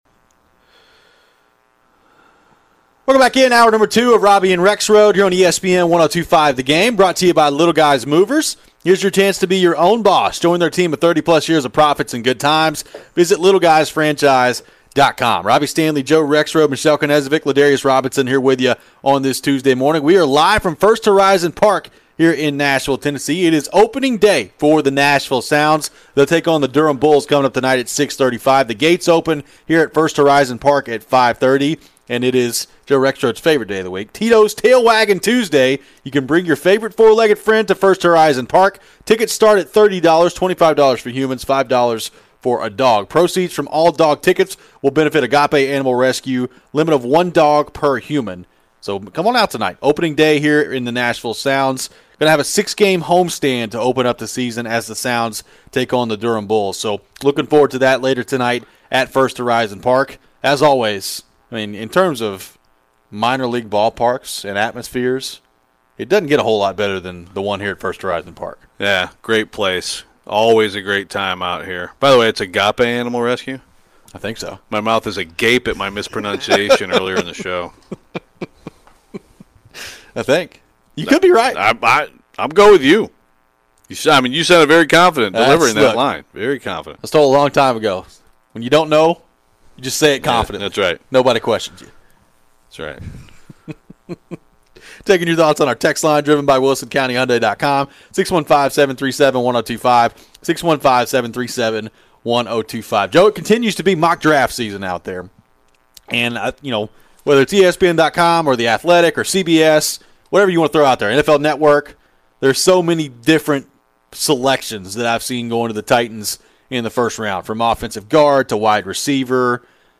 live from First Horizon Park. They take a look at different options for the Titans in the draft. Could they draft a QB in the 1st round?